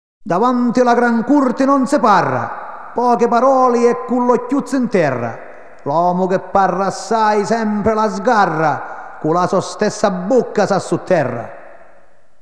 Style: Folk